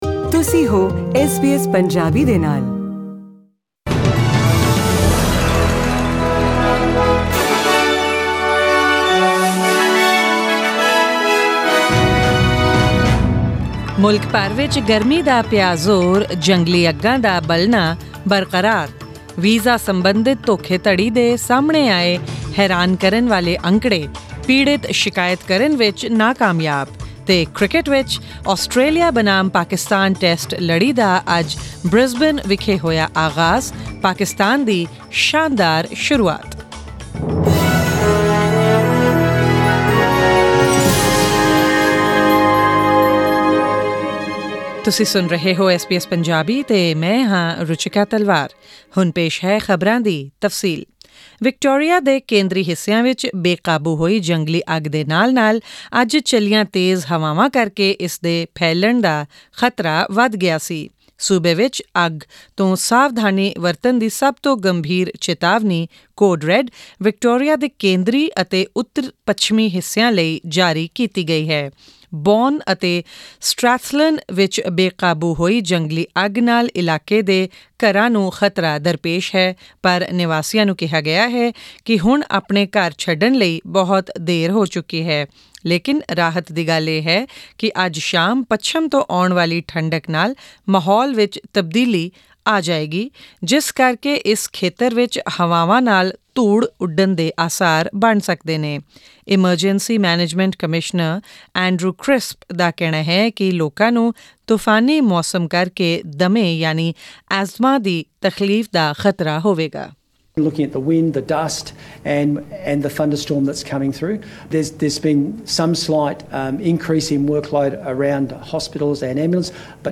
In cricket, the Australia vs Pakistan Test series began today in Brisbane, visitors off to a comfortable start Click on the player at the top of the page to listen to the news bulletin in Punjabi.